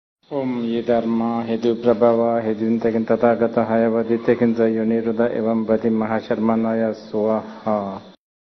缘起咒 诵经 缘起咒--宗萨钦哲仁波切 点我： 标签: 佛音 诵经 佛教音乐 返回列表 上一篇： 心静如水 下一篇： 往生的祝福 相关文章 25.